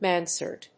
音節man・sard 発音記号・読み方
/mˈænsɑɚd(米国英語)/